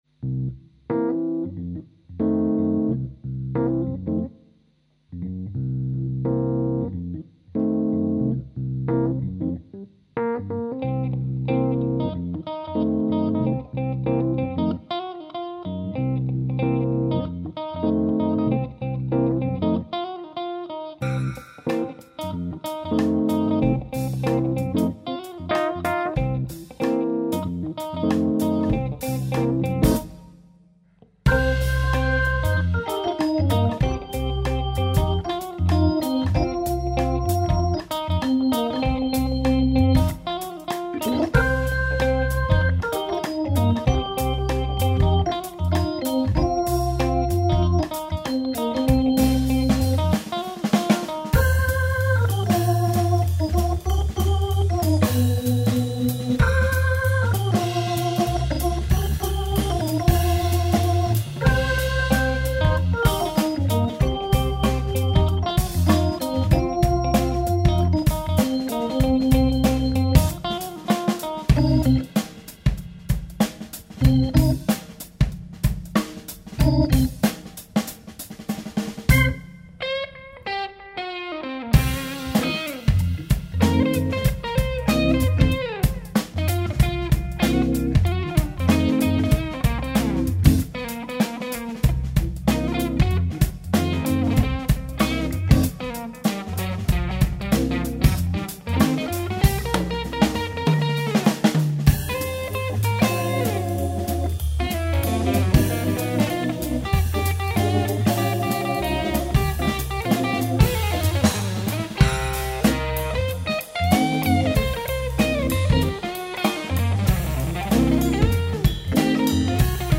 Le projet avec le trio jazz funk avance, on a enregistré une maquette le WE dernier.
ça groove